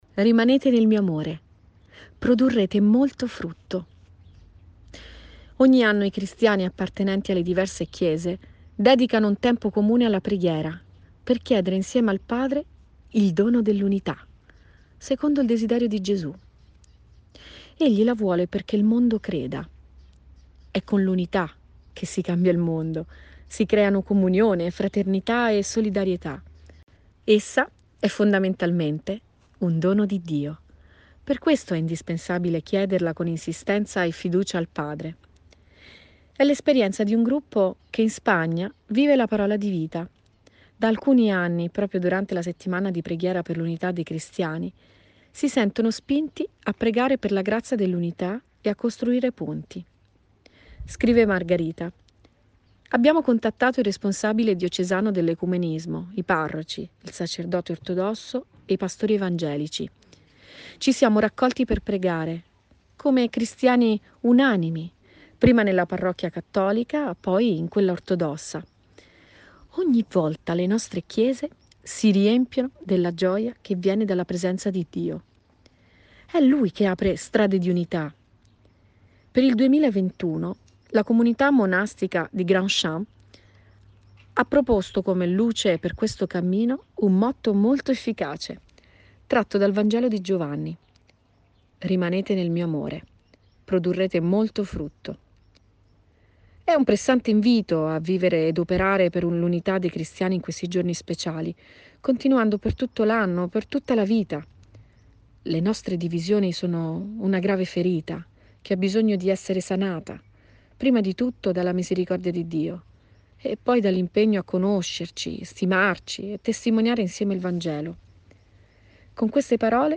In profondità > Audioletture
Lorena Bianchetti legge la Parola di vita di gennaio
«Rimanete nel mio amore: produrrete molto frutto» (Cf. Gv 15, 5-9). Legge Lorena Bianchetti, popolare conduttrice televisiva.